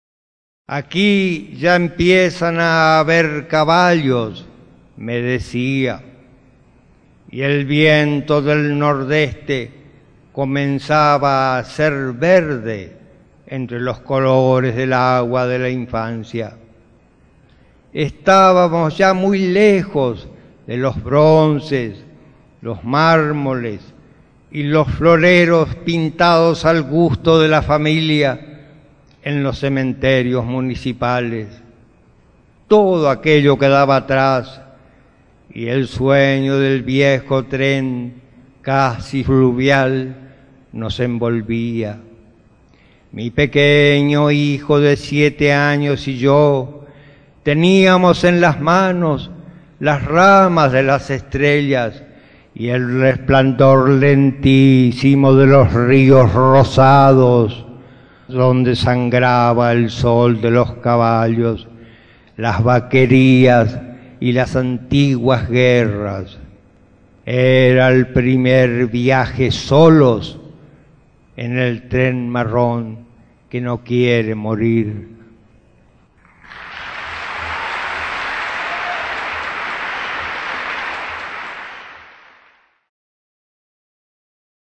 El audio pertenece las "Memorias del IX Festival Internacional de Poesía de Medellín", disco compacto editado por la revista Prometeo, organizadora de dicho Festival, actualmente el de mayor concurrencia a nivel mundial. La grabación en vivo contiene las voces de los 25 participantes al IX Festival, entre las cuales pueden oirse lecturas en guaraní, punjabi, inglés, sueco, árabe, francés, japonés, tuareg y alemán, con sus respectivas traducciones a nuestro idioma.